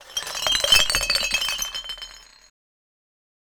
Glass multifall on floor 02
Glass_multi_fall_on_floor_02.wav